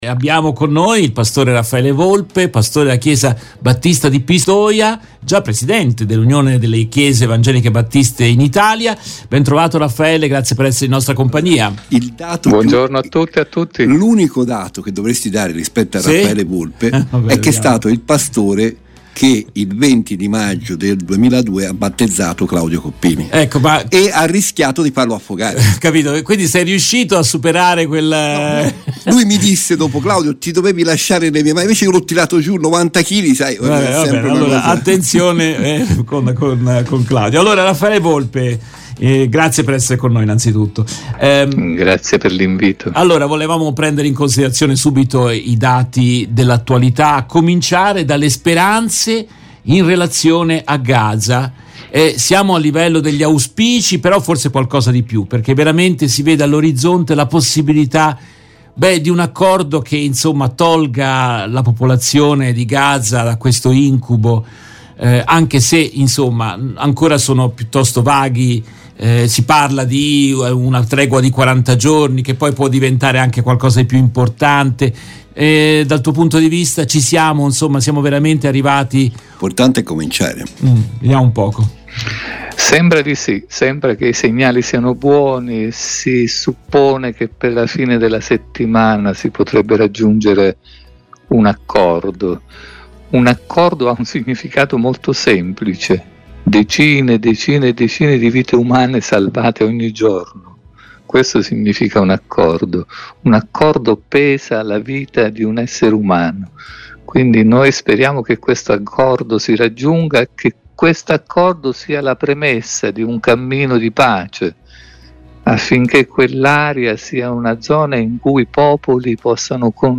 In questa intervista